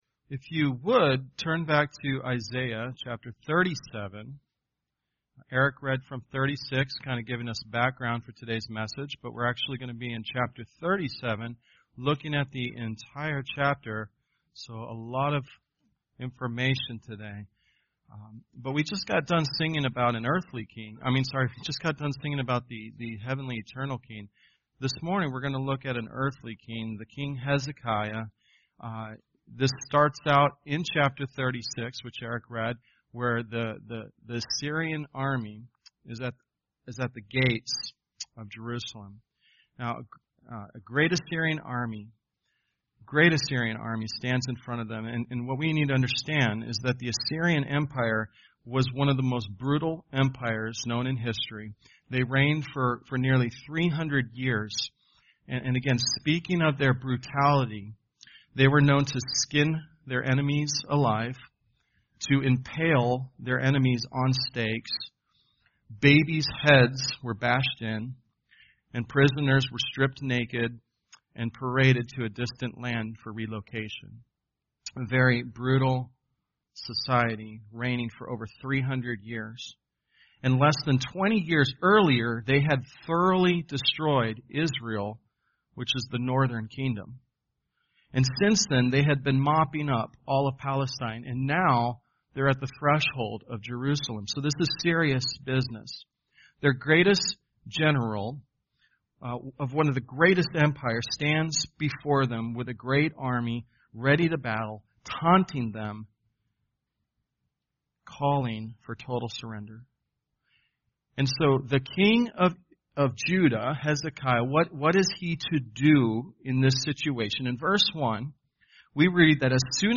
Tagged with Sunday Sermons